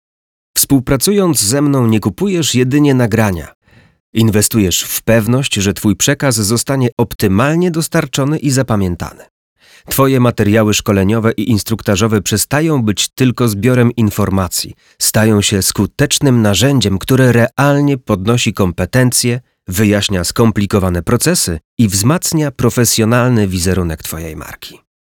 Narracje lektorskie do szkoleń, onboardingów, szkolenia, BPH, prezentacji